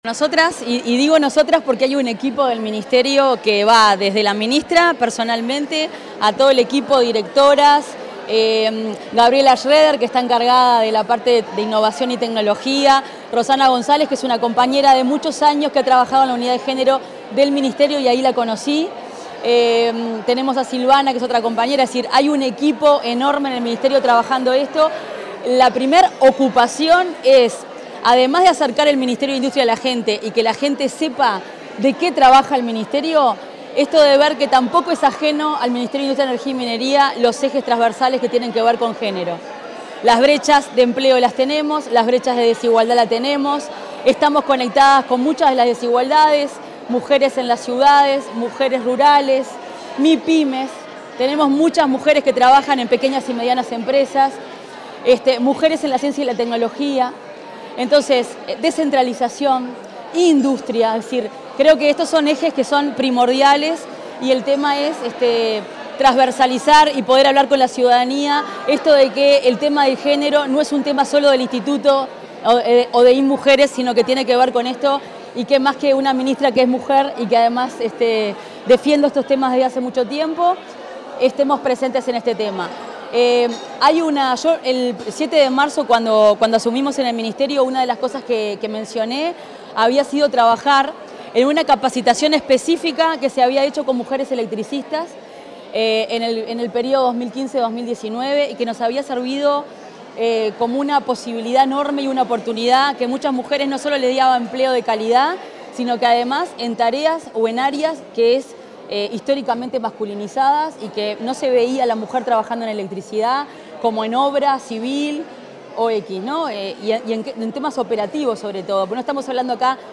Declaraciones de la ministra de Industria, Fernanda Cardona
Declaraciones de la ministra de Industria, Fernanda Cardona 23/07/2025 Compartir Facebook X Copiar enlace WhatsApp LinkedIn Al finalizar el acto de reinstalación del Consejo Nacional de Género para el período 2025-2030, la ministra de Industria, Energía y Minería, Fernanda Cardona, realizó declaraciones a la prensa.